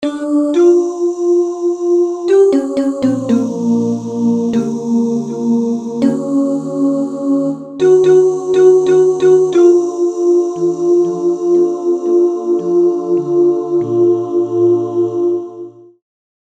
synth voices & a piano